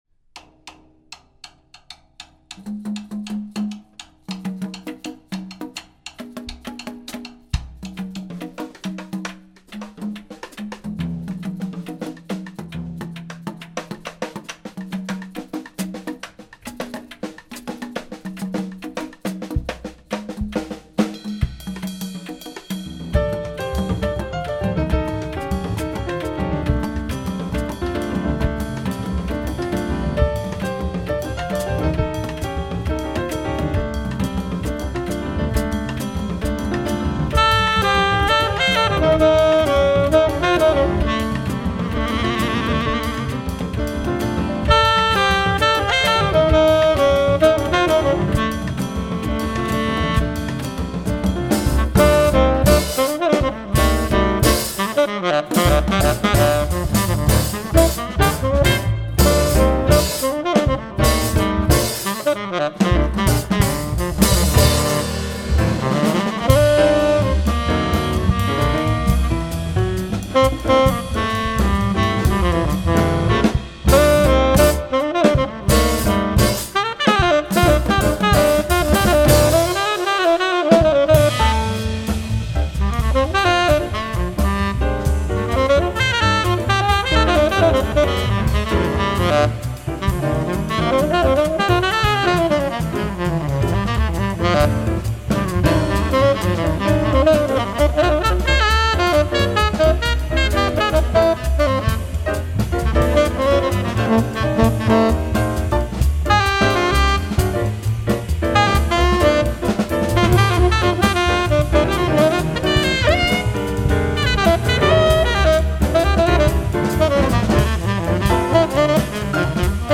Tenor Saxophonist